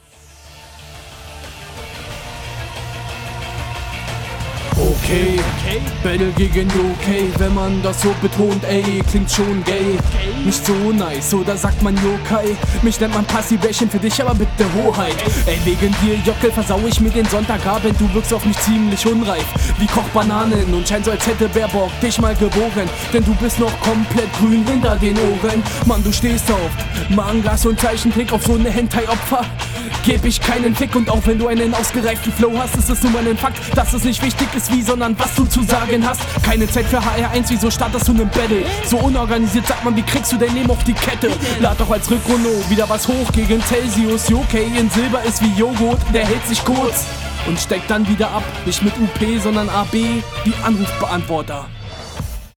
Flow: Der Flow ist meiner Meinung nach nicht so nice.